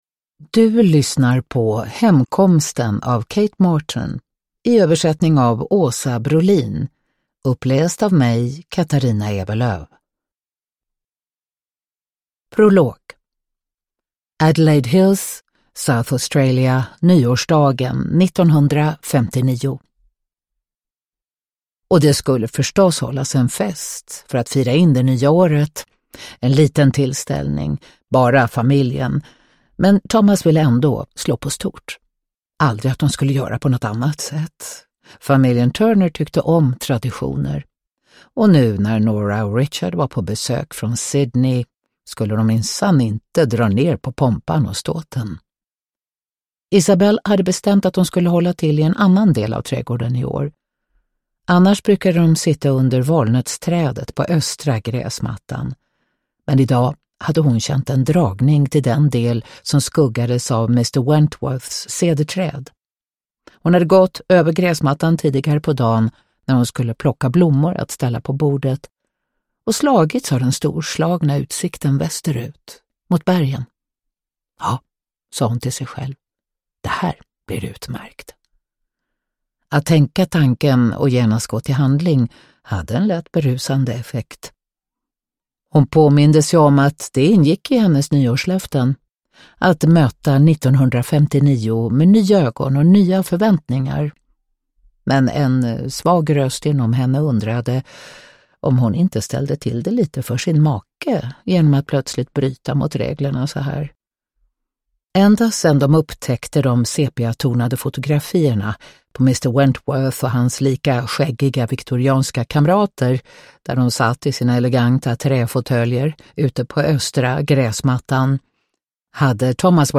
Uppläsare: Katarina Ewerlöf